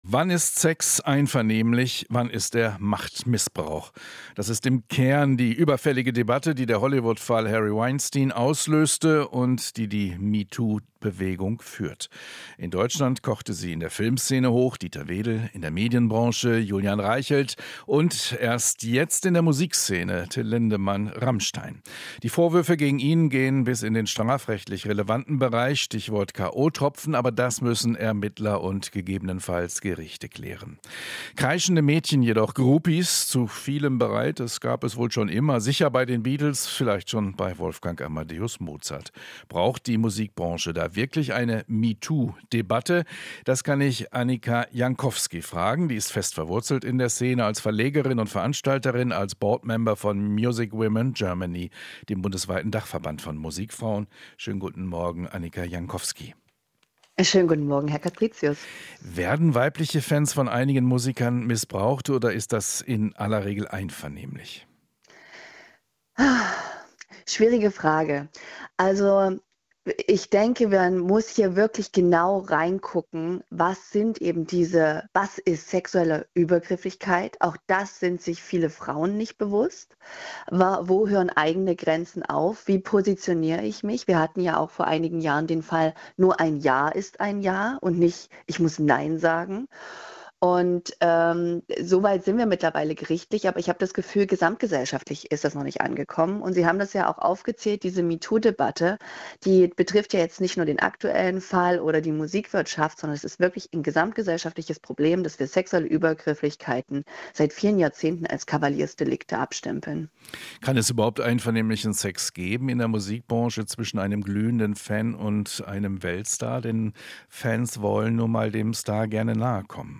Interview - Music Women* Germany: Backstage gehen ist kein Ja zu Sex